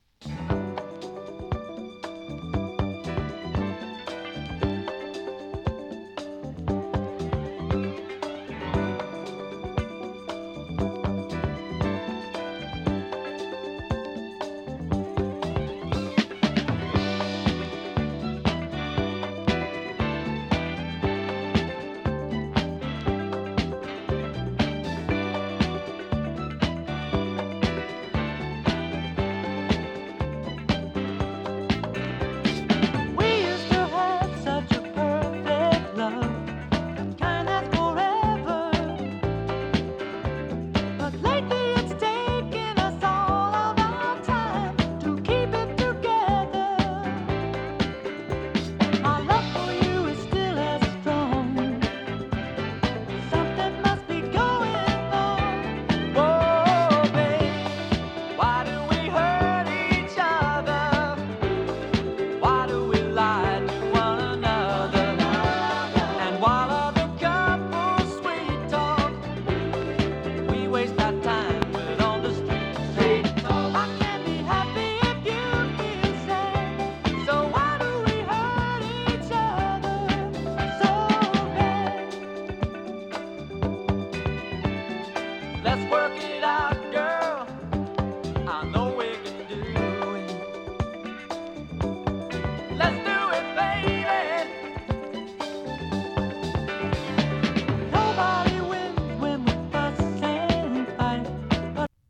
AOR ブリティッシュファンク 王道ソウル フリーソウル